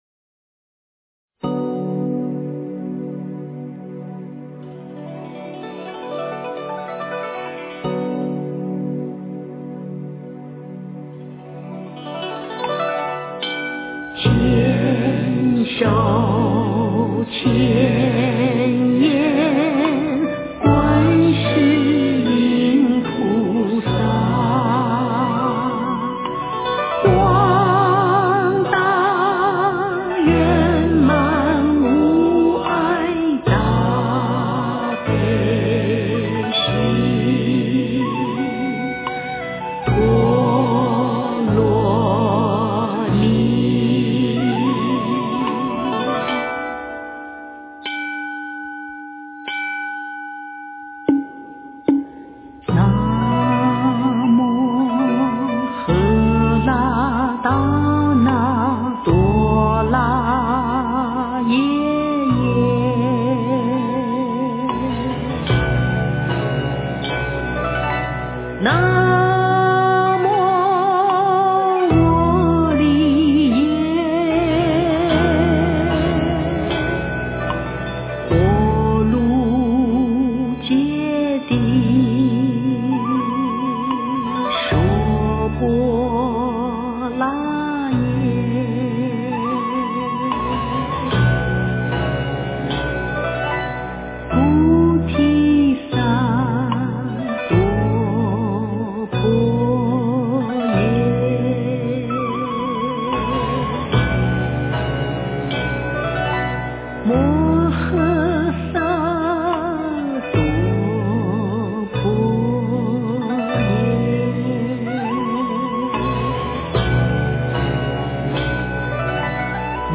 诵经
佛音 诵经 佛教音乐 返回列表 上一篇： 大悲咒 下一篇： 般若波罗蜜多心经 相关文章 感恩一切--李娜 感恩一切--李娜...